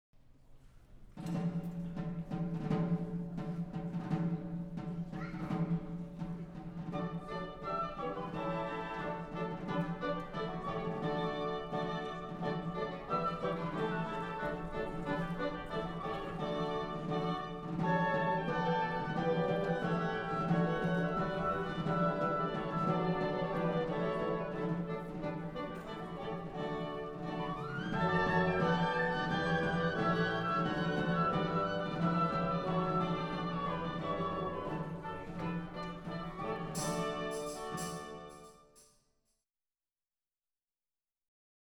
Dance
a lively setting